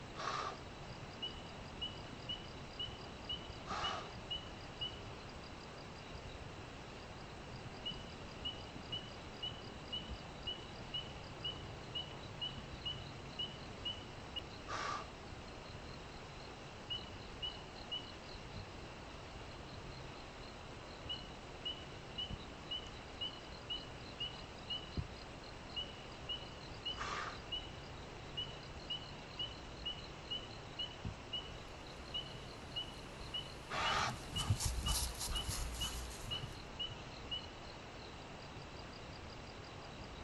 Ήχητική εγκατάσταση: δάσος και ελάφι.wav (7.37 MB)
Δάσος
The viewers’ gaze meets that one of the deer, which comes to life in front of them under the sound of its sharp breath.